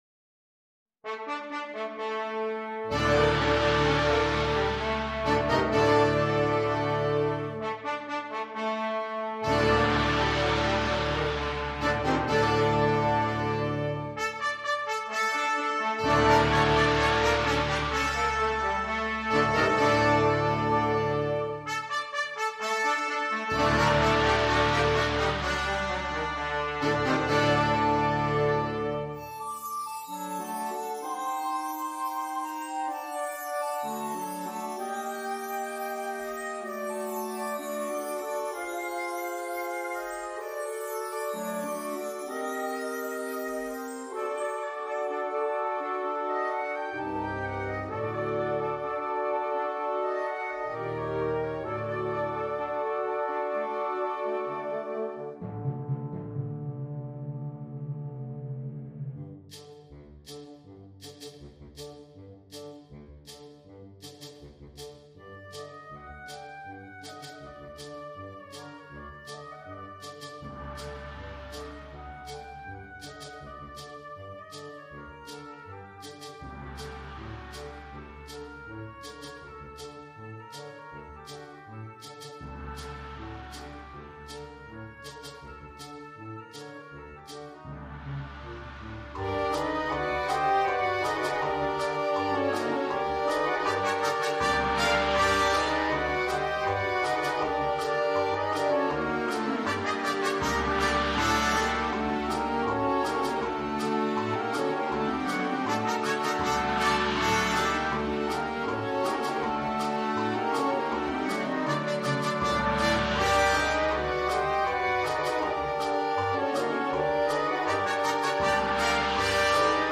suggestiva e coinvolgente fantasia per banda giovanile